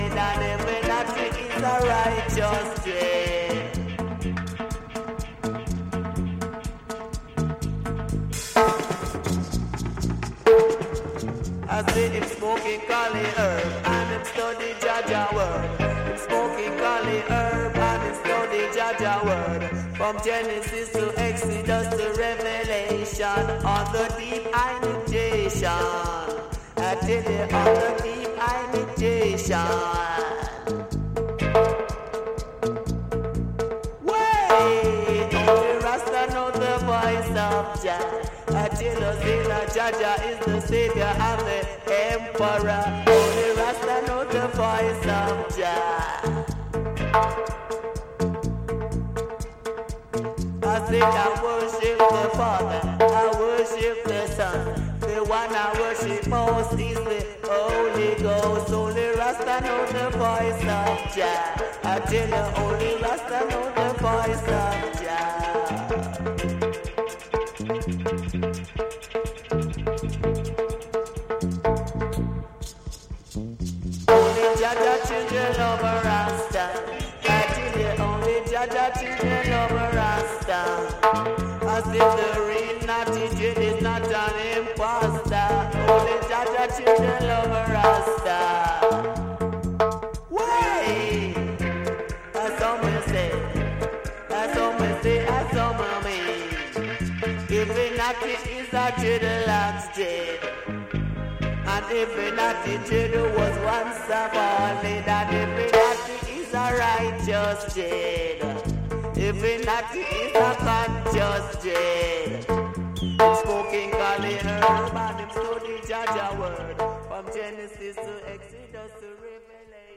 Dub Reggae